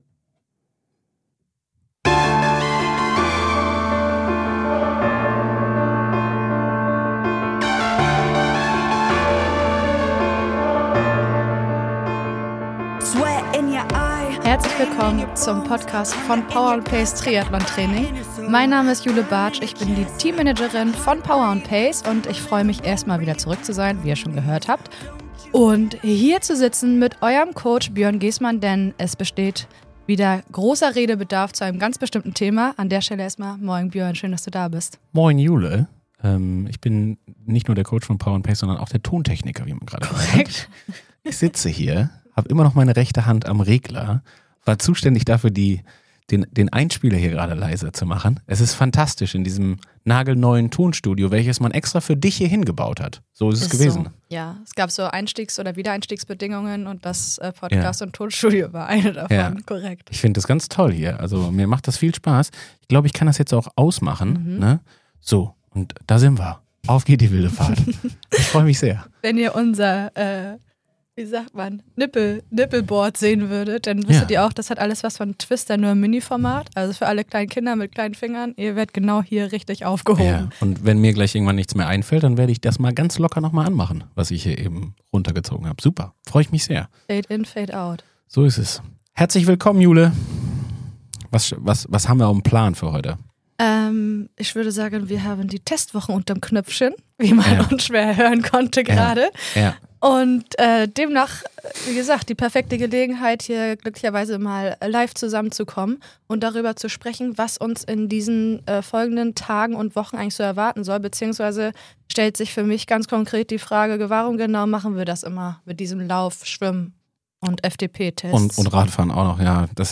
00:00 – Willkommen im neuen Tonstudio: Intro & Begrüßung.